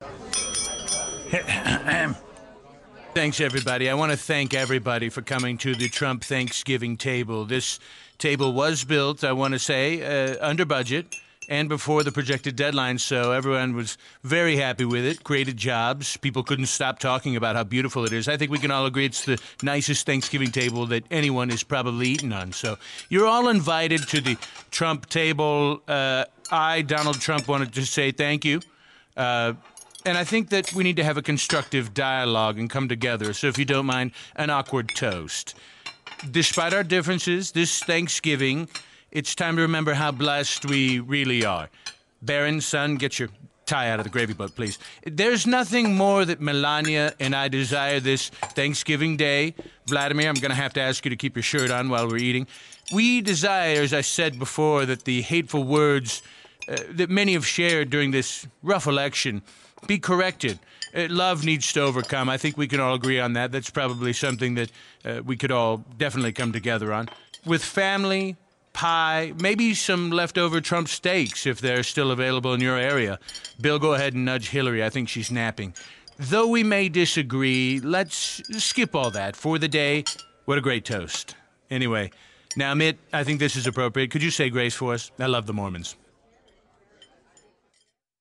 dramatization of the President-elect's Thanksgiving dinner